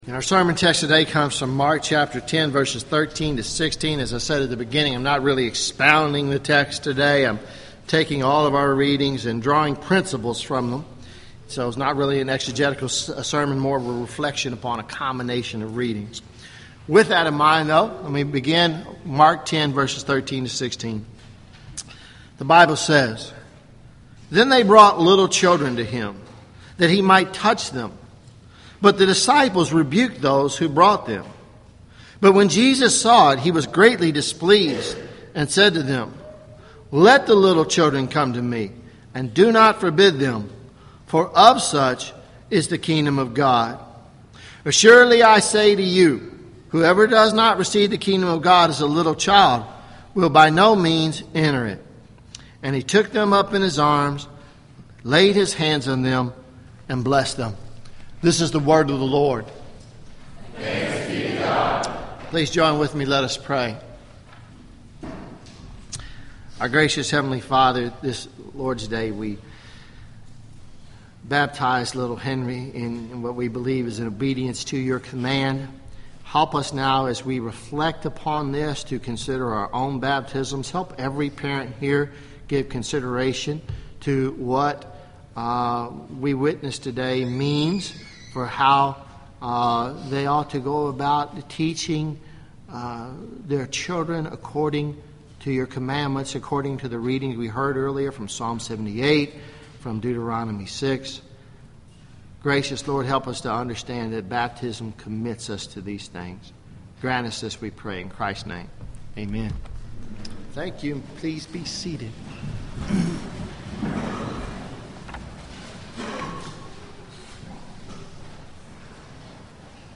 from Mark 10:13-16 at Christ Covenant Presbyterian Church, Lexington, Ky.